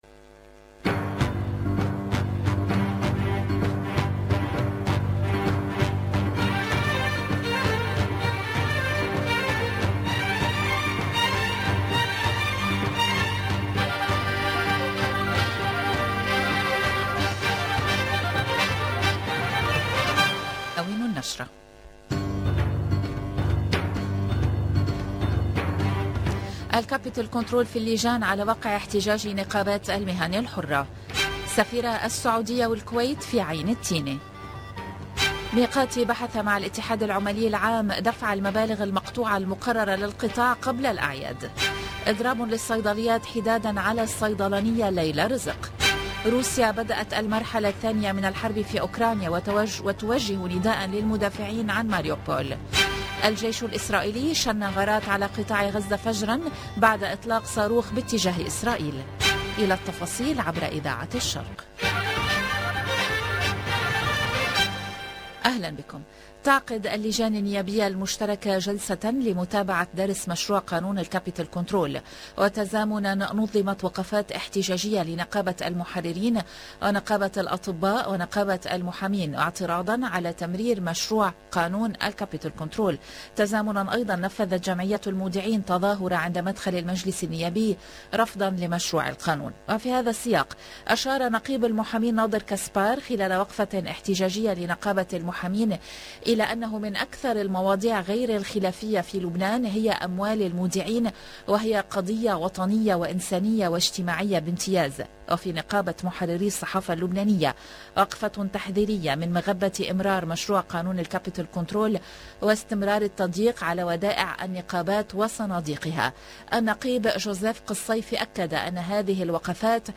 EDITION DU JOURNAL DU LIBAN DE 17 H DU 19/4/2022